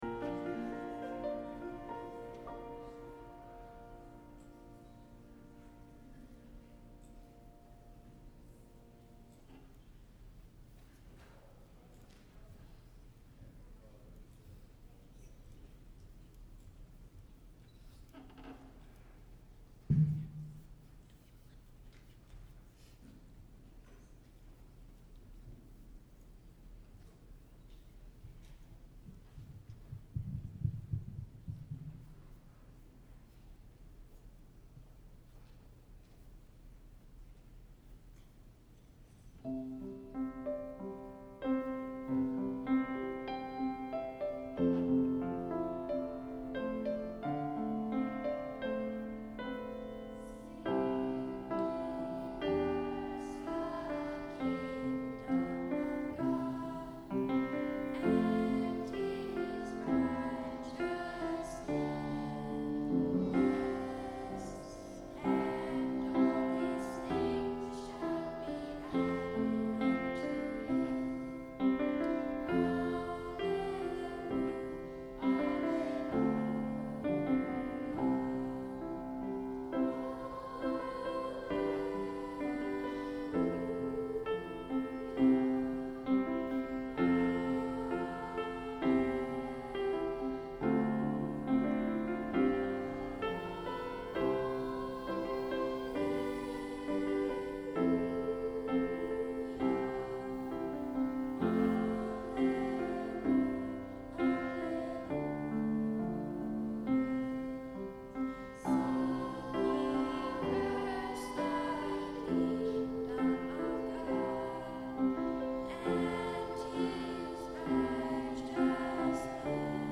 Sunday Sermon May 14, 2023